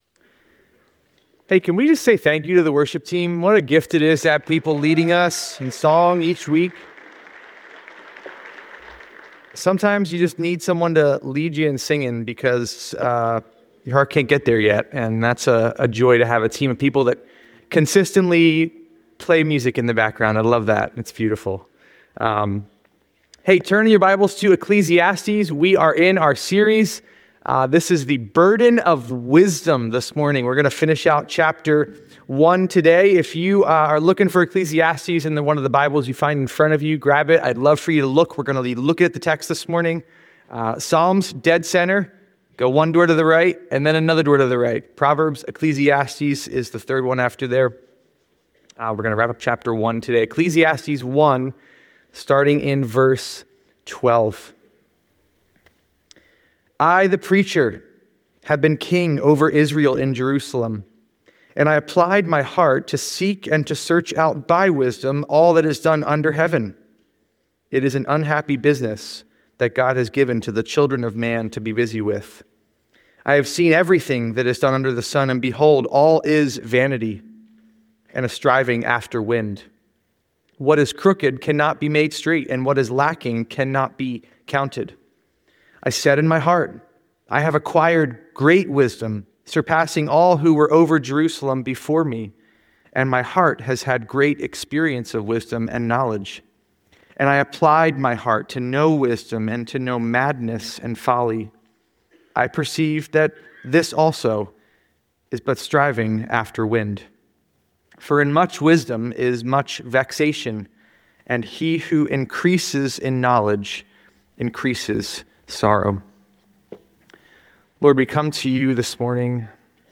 The sermon is a detailed examination of Ecclesiastes Chapter 1, focusing on Solomon's pursuit of wisdom.